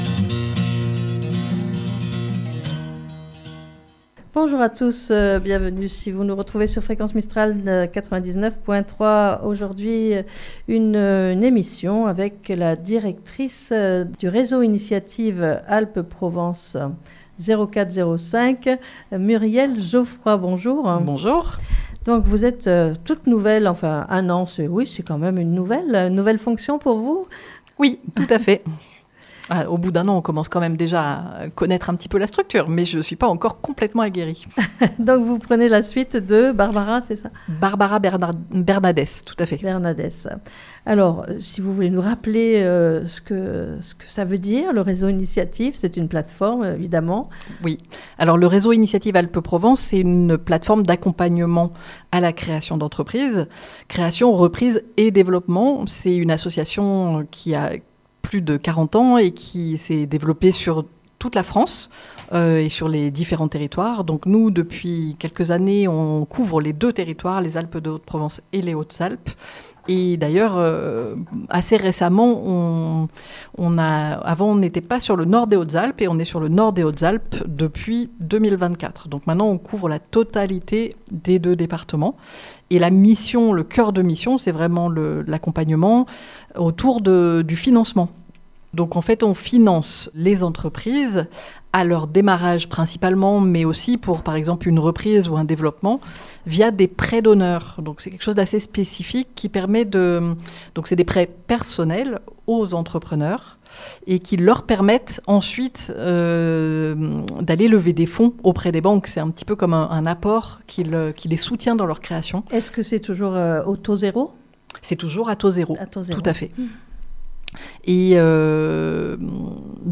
est notre invitée.